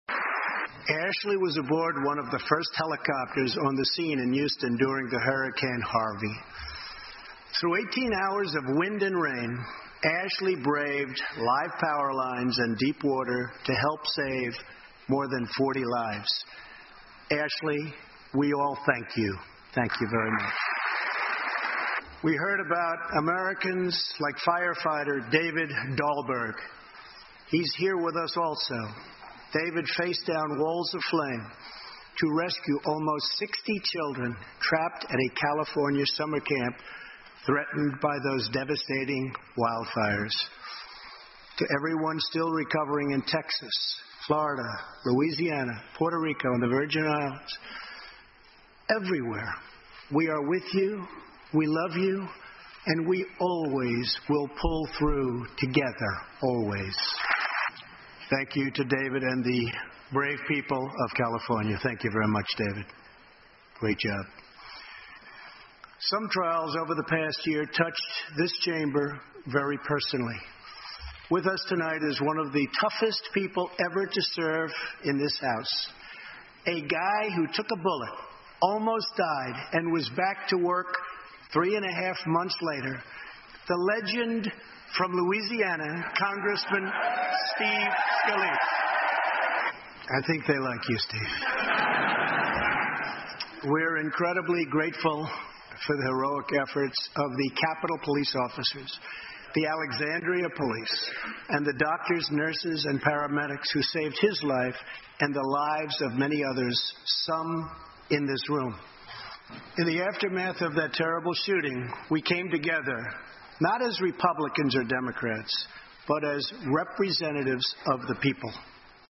欧美名人演讲 第95期:美国总统川普首次国情咨文演讲(2) 听力文件下载—在线英语听力室